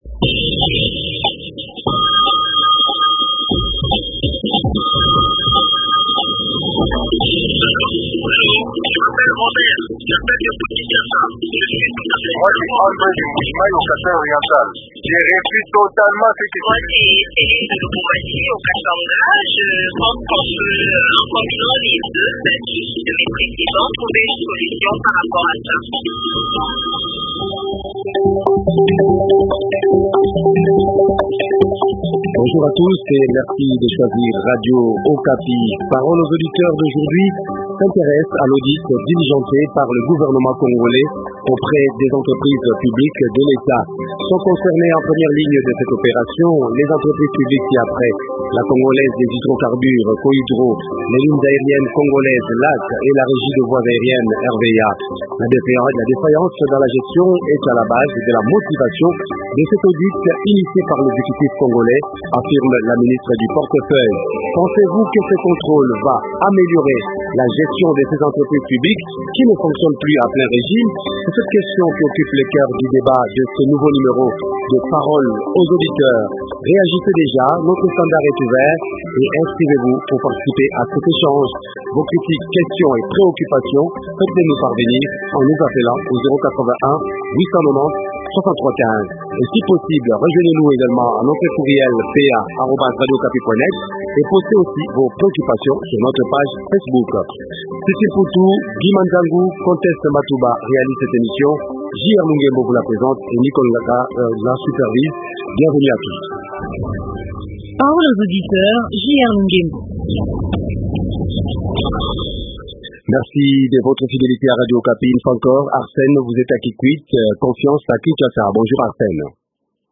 économiste.